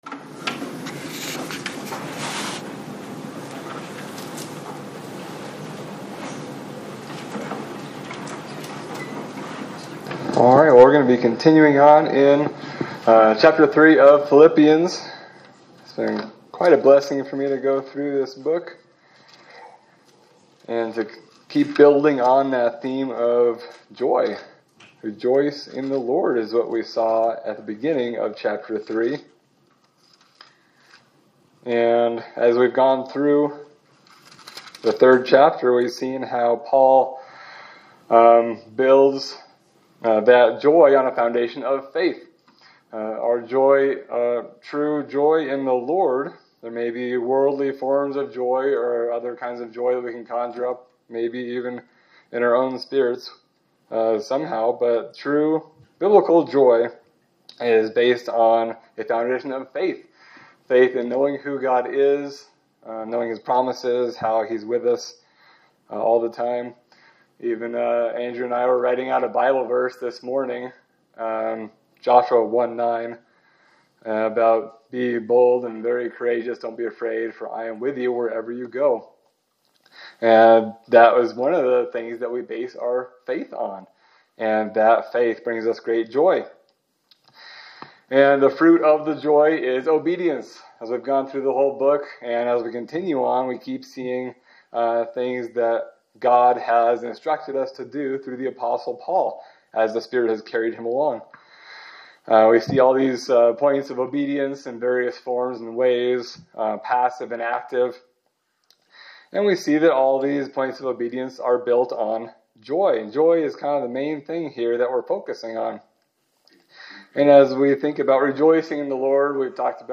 Sermon for October 26, 2025
Service Type: Sunday Service